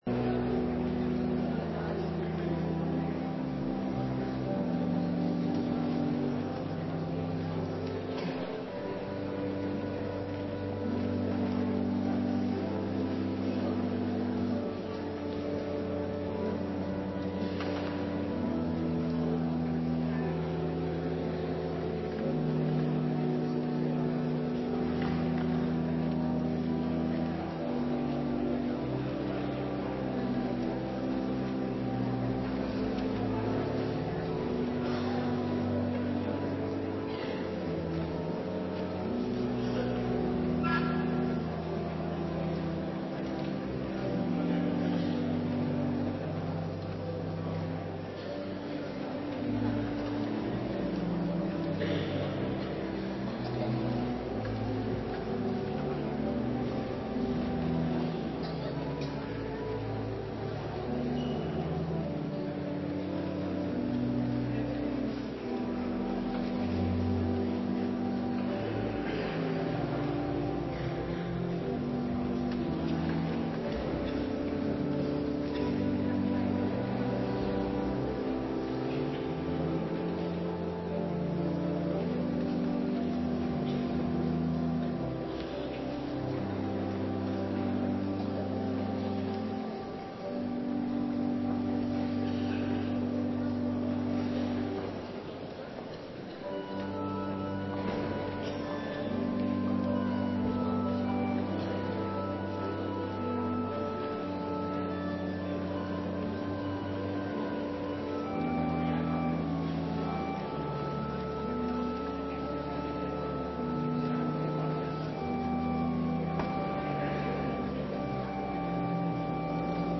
kerkdienst.mp3